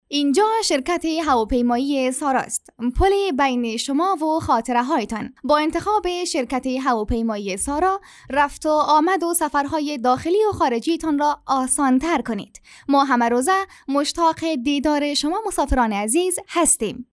Female
Young
Commercial